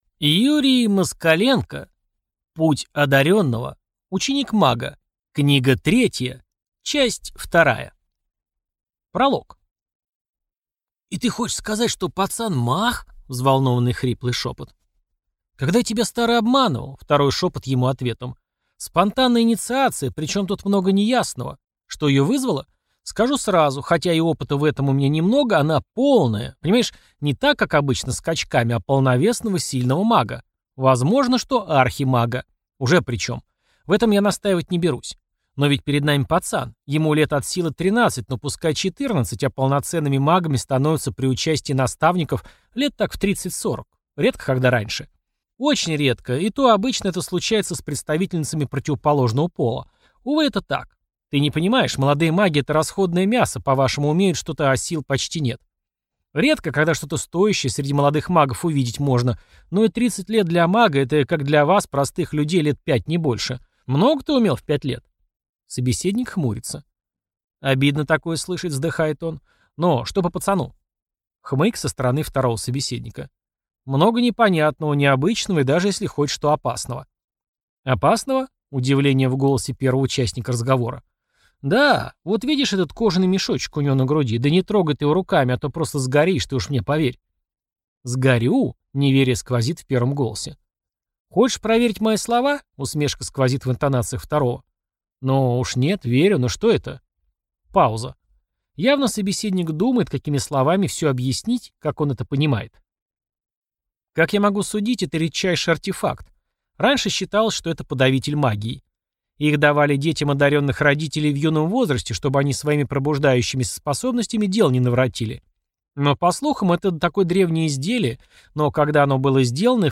Аудиокнига Путь одарённого. Ученик мага. Книга третья. Часть вторая | Библиотека аудиокниг